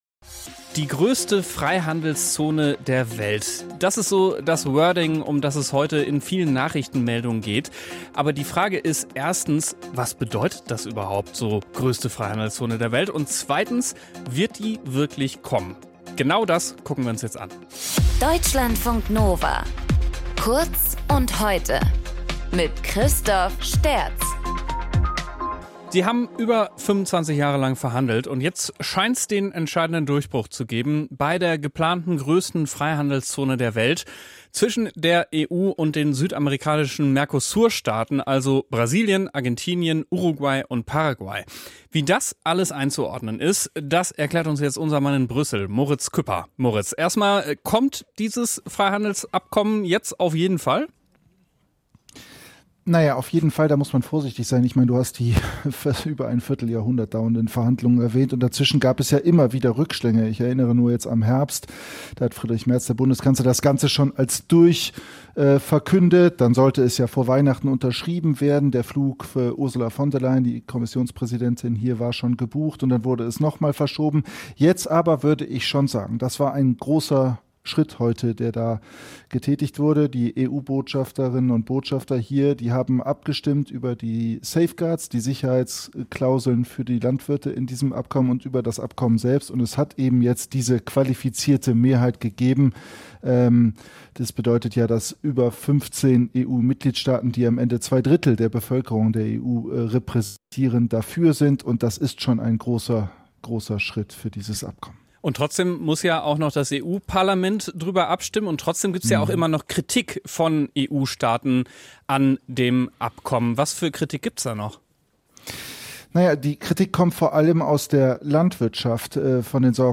Moderator: